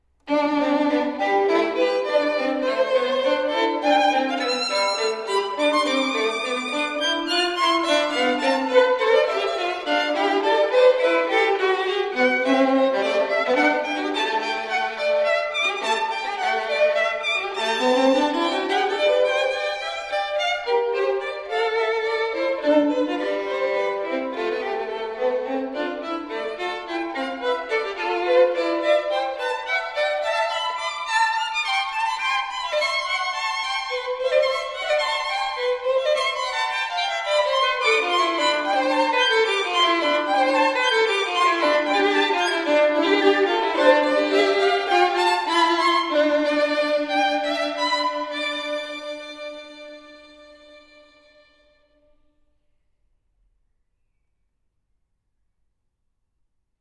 Violine
Dorisch